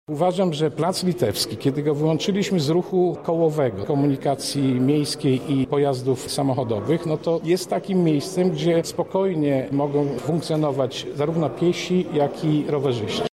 Swoją wizję aktywności na Placu Litewskim przedstawił radny Piotr Gawryszczak: